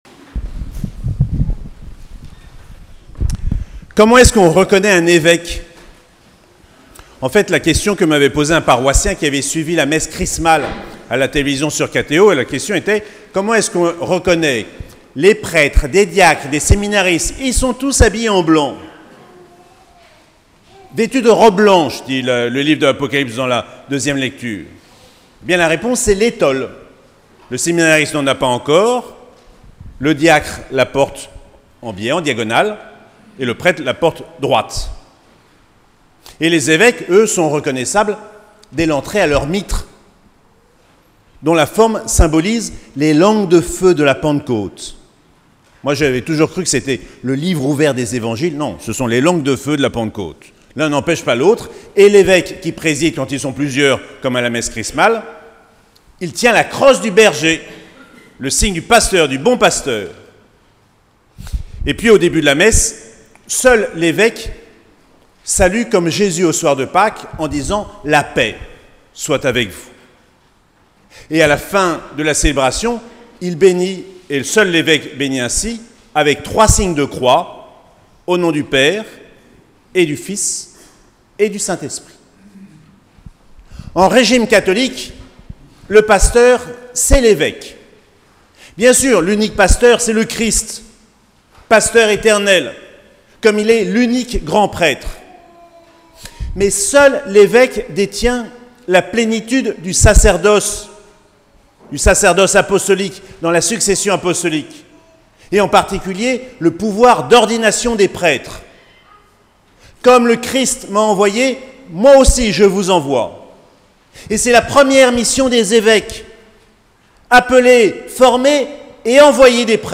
4ème dimanche de Pâques - 8 mai 2022
Toutes les homélies